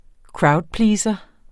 Udtale [ ˈkɹɑwdˌpliːsʌ ]